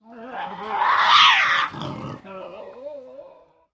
Крик атаки